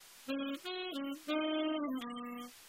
Slowly, in pieces